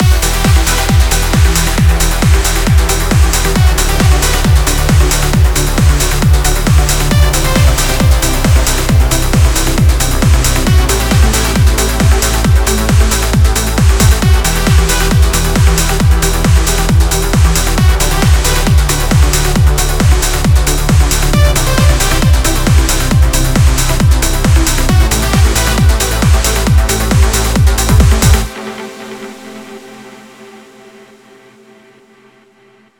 На этот раз транс. Трек с нуля, сделано за час, все сочинено и придумано в риал тайм.